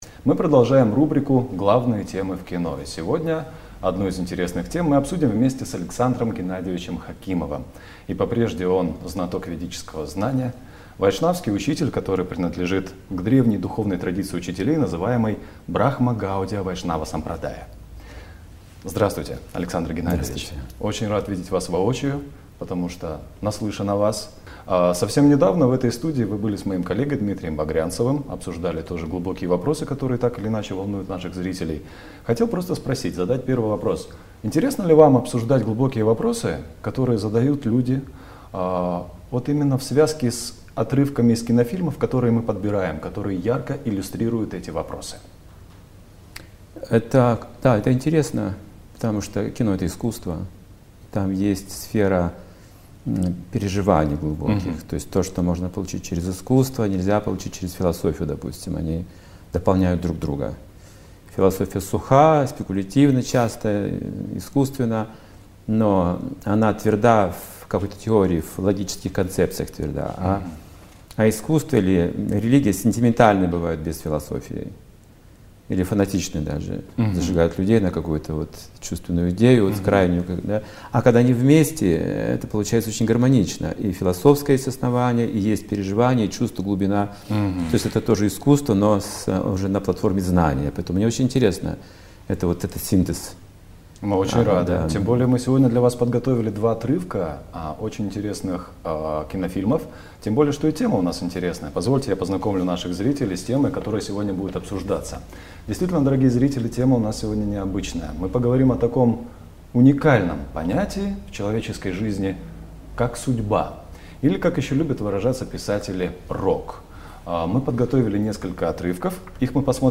2020.02, Алматы, Интервью в программе "Главные темы в кино", Фильм третий - Судьба